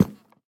Minecraft Version Minecraft Version 1.21.5 Latest Release | Latest Snapshot 1.21.5 / assets / minecraft / sounds / block / cherry_wood_hanging_sign / step1.ogg Compare With Compare With Latest Release | Latest Snapshot
step1.ogg